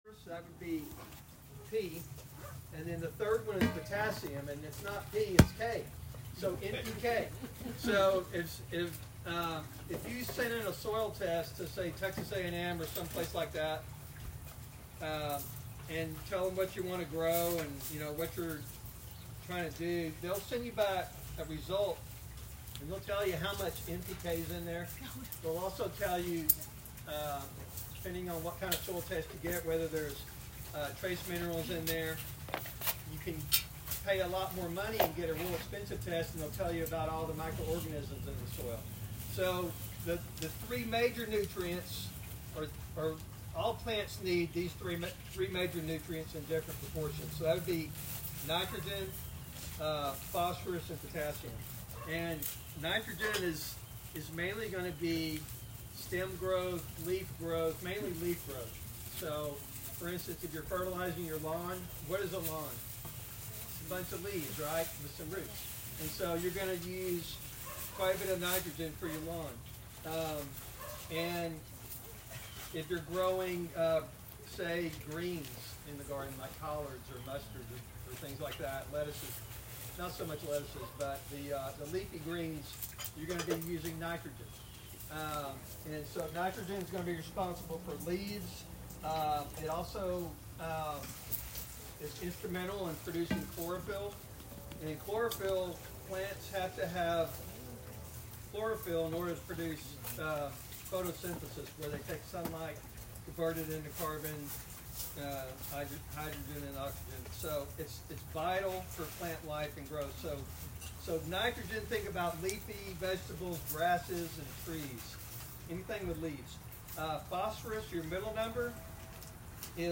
The meetings are held in individual homes, creating a personalized, welcoming environment.
The audio of the talk was captured and is available at the link below.